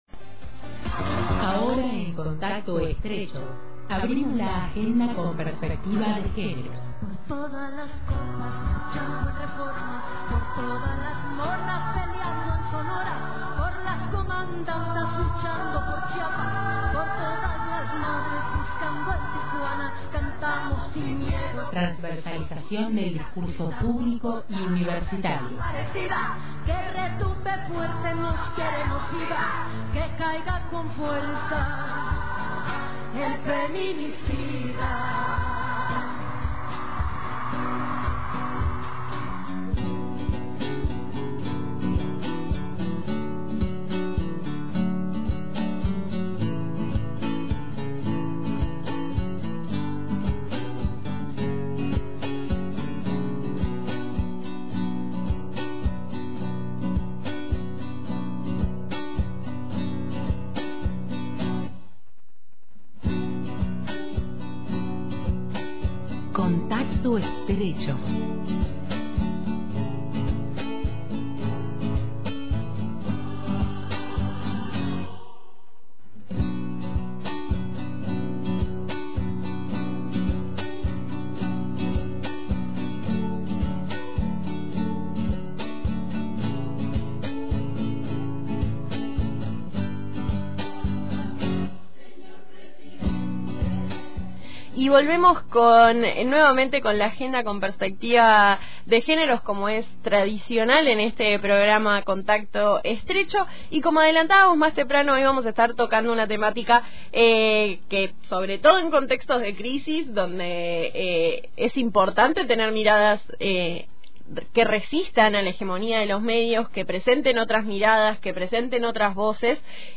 Allí se presenta la disminución de la participación de mujeres y feminidades en la conducción, frente al dominio de los varones cis en estos roles. Escuchamos las voces de dos integrantes de la productora